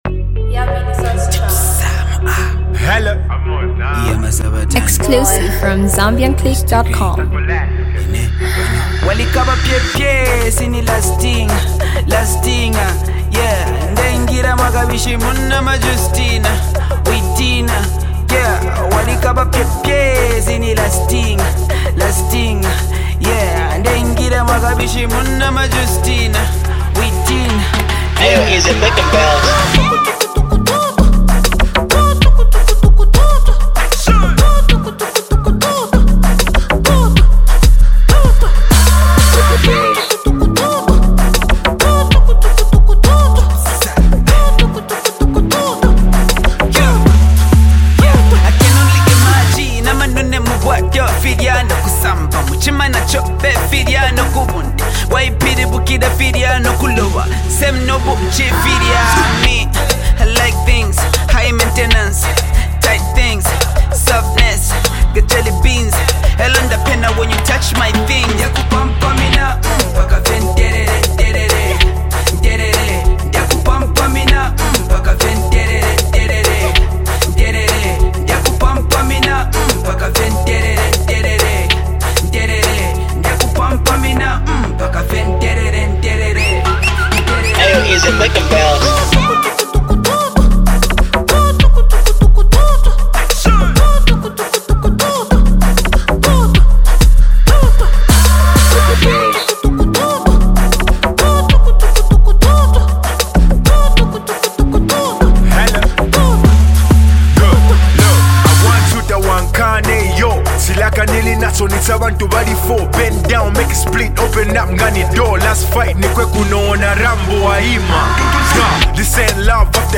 jamming song
a street anthem and a clubbing jam